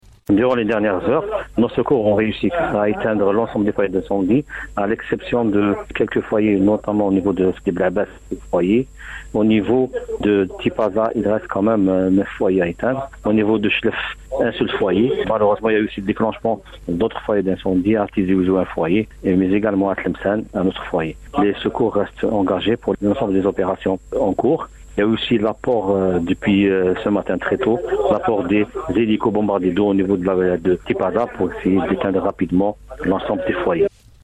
au micro de la radio chaine 3